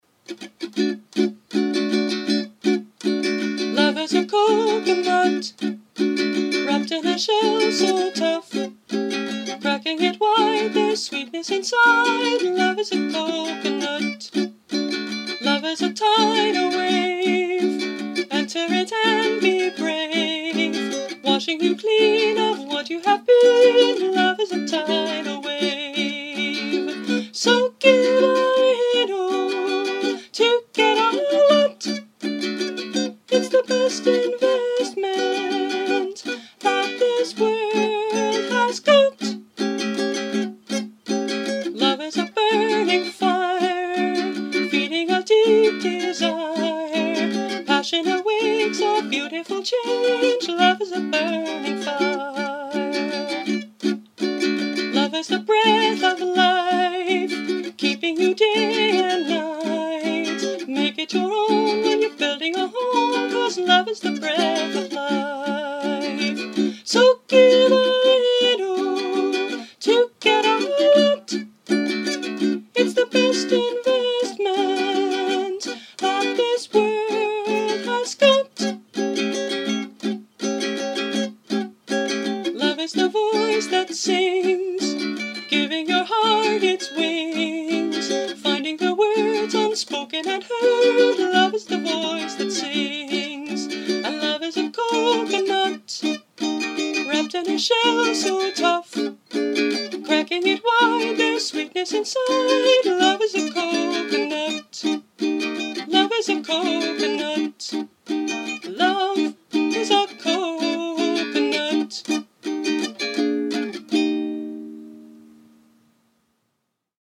I must say, the closest this song comes to the occasion is a tropical theme, but a fun song anyway.
Instrument: Brio – Red Cedar Concert Ukulele